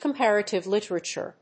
アクセントcompárative líterature
comparative+literature.mp3